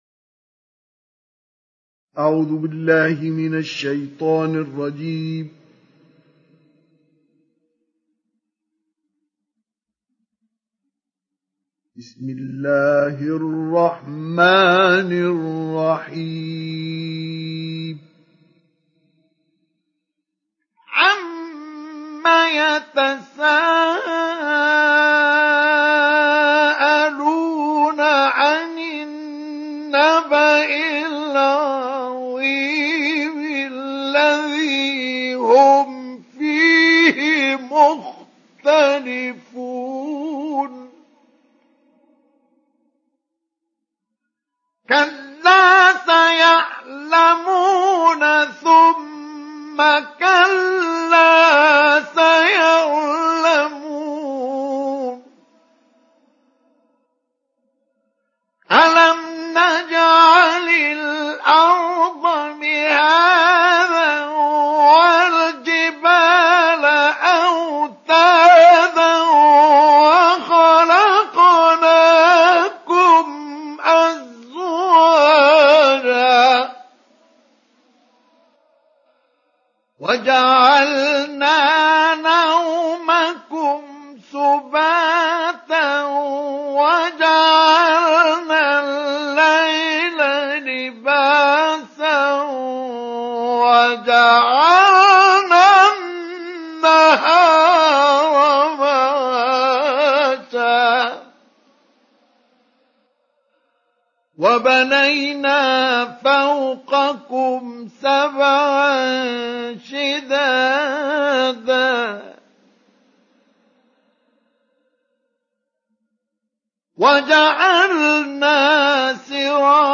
Surah An Naba mp3 Download Mustafa Ismail Mujawwad (Riwayat Hafs)